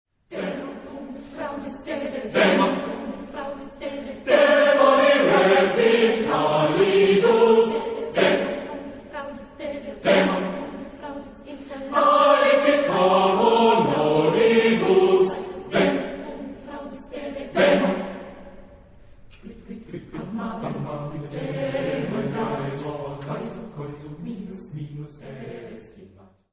Genre-Style-Form: Contemporary ; Choir ; Secular
Type of Choir: SATB  (4 mixed voices )
Tonality: various